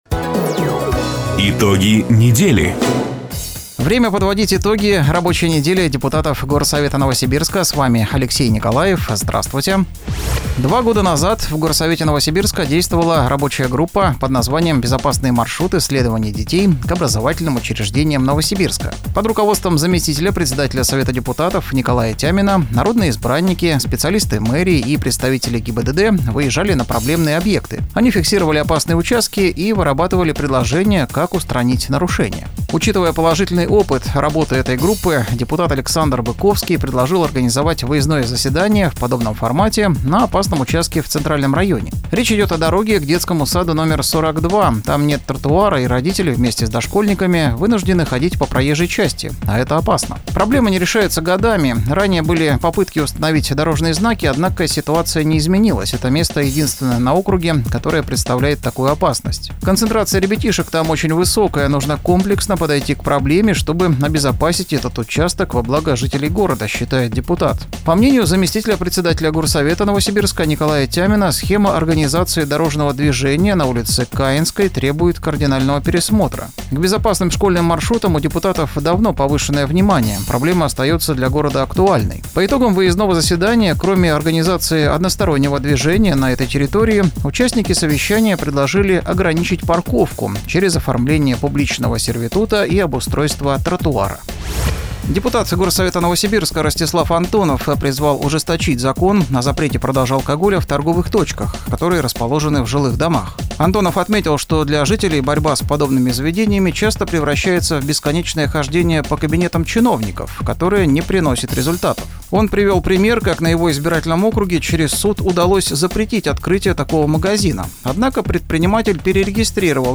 Запись программы "Итоги недели", транслированной радио "Дача" 15 июня 2024 года.